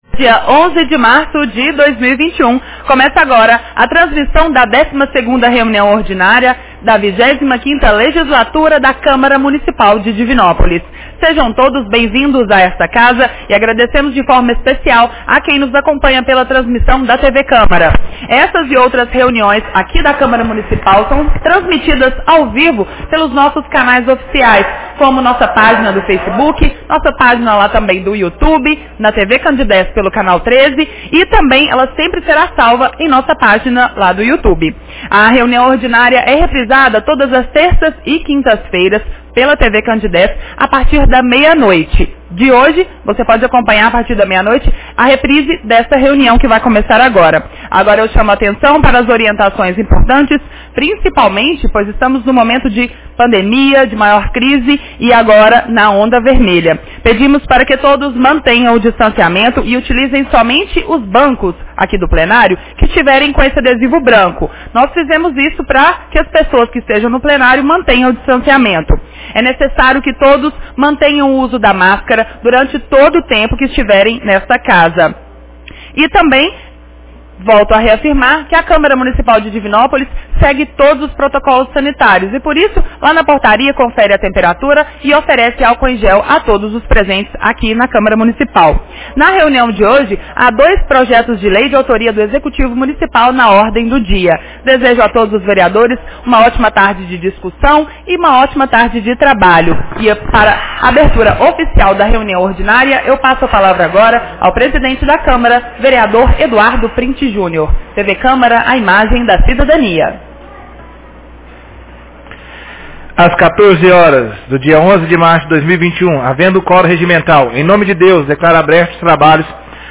Reunião Ordinária 12 de 11 de março 2021